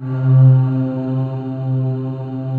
Index of /90_sSampleCDs/USB Soundscan vol.28 - Choir Acoustic & Synth [AKAI] 1CD/Partition D/18-HOLD VOXS